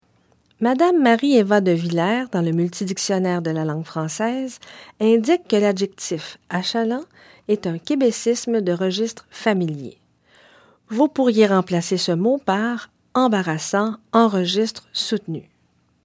Dans cet extrait, on entend achalant (au lieu de embarrassant ). Notez qu’on entend également le terme voé , un phénomène oral de l’ancien français, et feele , un phénomène oral d’un milieu bilingue.